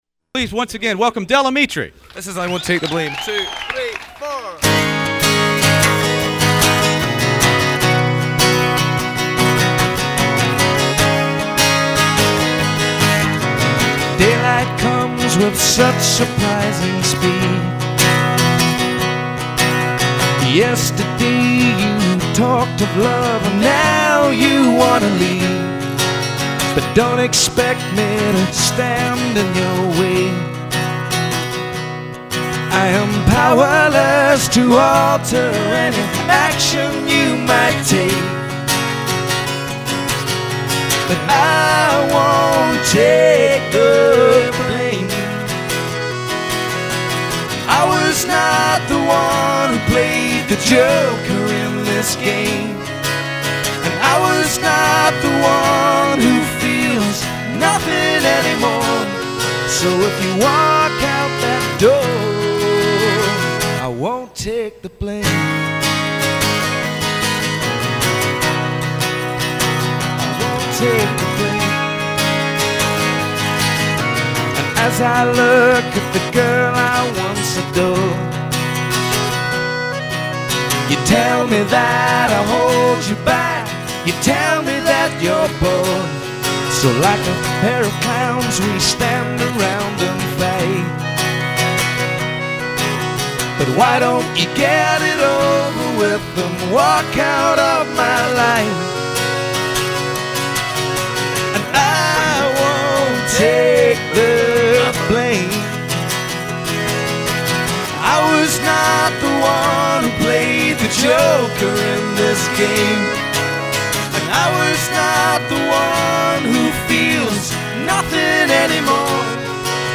accordion
acoustically